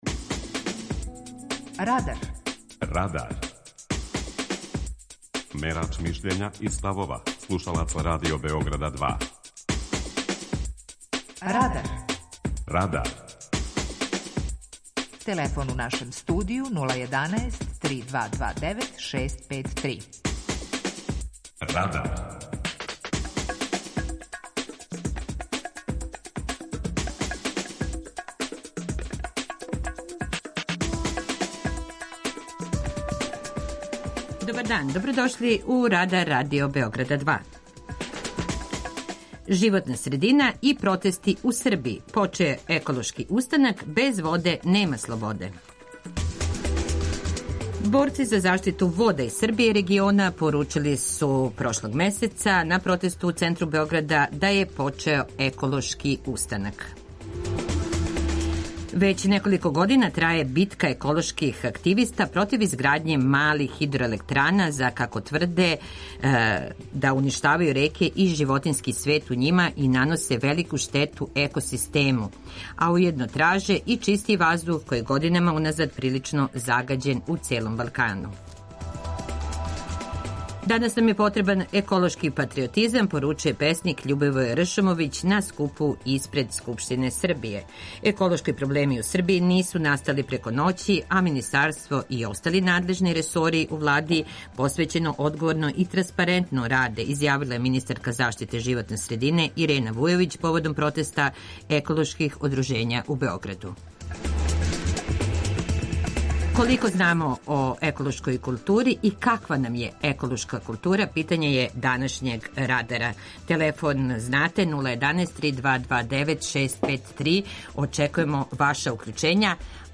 Питање Радара: Каква нам је еколошка култура? преузми : 18.98 MB Радар Autor: Група аутора У емисији „Радар", гости и слушаоци разговарају о актуелним темама из друштвеног и културног живота.